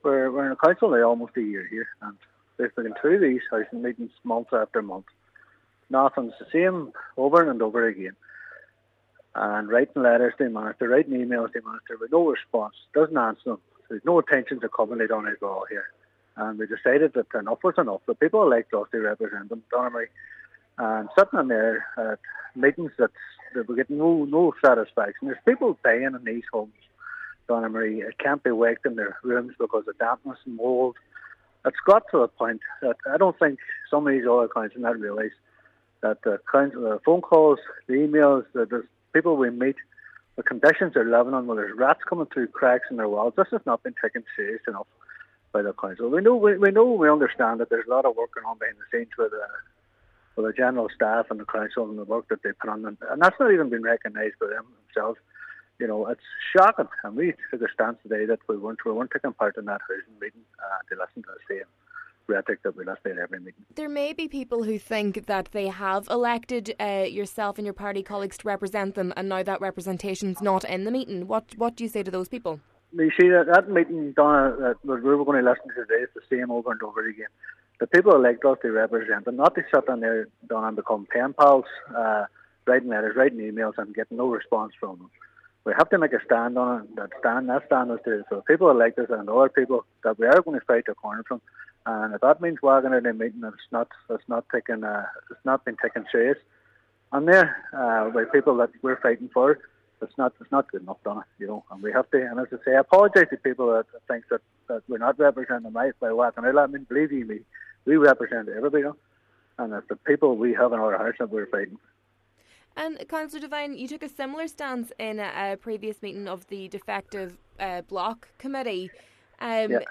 Speaking to Highland Radio News, Cllr Devine says the monthly meetings dedicated to housing are not productive, and in his year of sitting on the council, nothing substantial has been achieved: